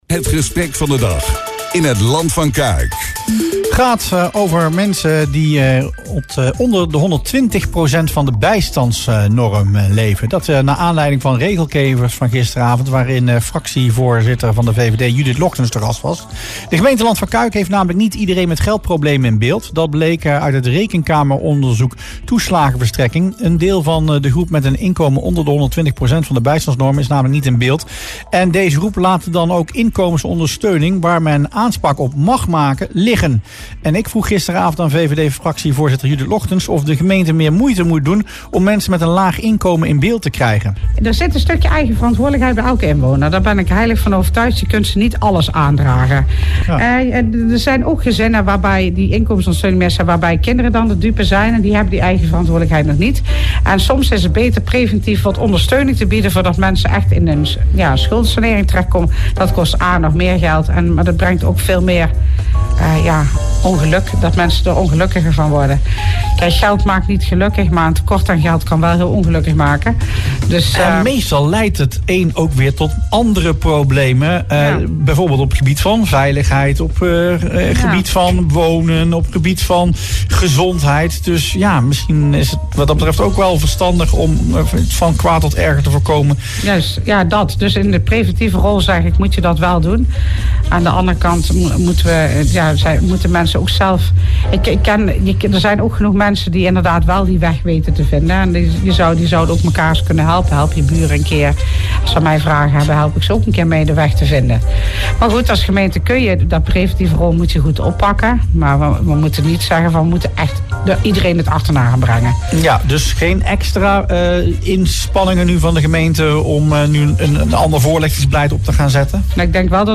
“Een tekort aan geld kan mensen diep ongelukkig maken,” zei zij dinsdagavond in het radioprogramma Regelkevers.
Judith Logtens van de VVD in Regelkevers